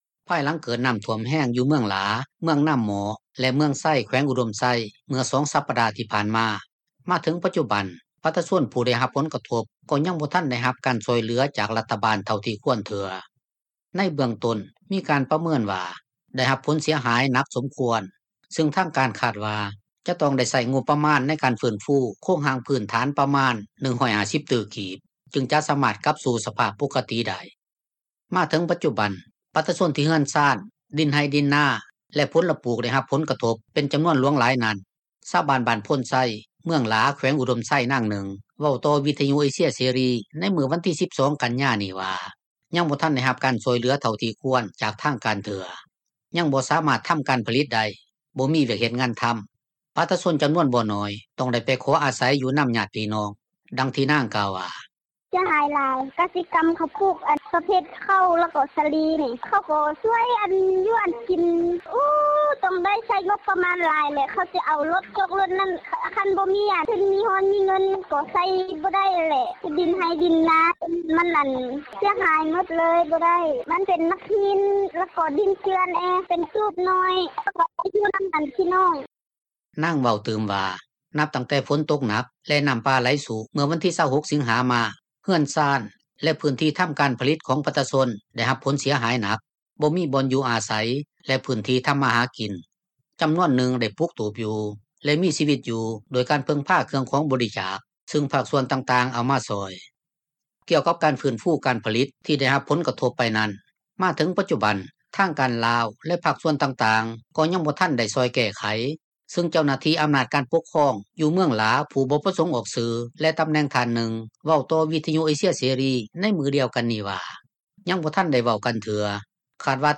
ມາເຖິງປັດຈຸບັນ ປະຊາຊົນທີ່ເຮືອນຊານ, ດິນໄຮ່, ດິນນາ ແລະຜົລປູກ ໄດ້ຮັບຜົລກະທົບ ເປັນຈໍານວນຫລວງຫລາຍນັ້ນ ຊາວບ້ານບ້ານໂພນໄຊ ເມືອງຫລາ ແຂວງອຸດົມໄຊ ນາງນຶ່ງ ເວົ້າຕໍ່ວິທຍຸເອເຊັຽເສຣີ ໃນມື້ວັນທີ 12 ກັນຍານີ້ວ່າ ຍັງບໍ່ທັນໄດ້ຮັບການຊ່ວຍເຫລືອ ເທົ່າທີ່ຄວນຈາກທາງການເທື່ອ, ຍັງບໍ່ສາມາດ ທໍາການຜລິຕໄດ້, ບໍ່ມີວຽກເຮັດງານທໍາ, ປະຊາຊົນຈໍານວນບໍ່ໜ້ອຍ ຕ້ອງໄດ້ໄປຂໍອາສັຍຢູ່ນໍາຍາດພີ່ນ້ອງ.